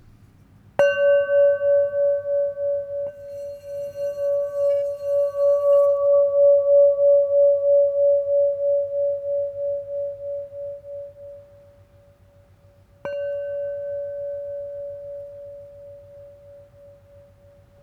D Note 5″ Singing Bowl